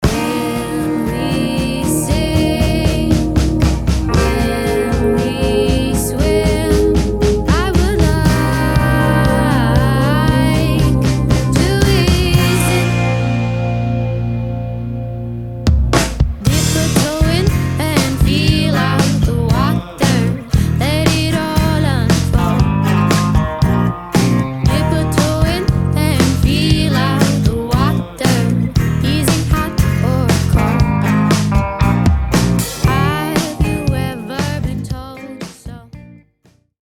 Drum Tracking
Drum-Tracking.mp3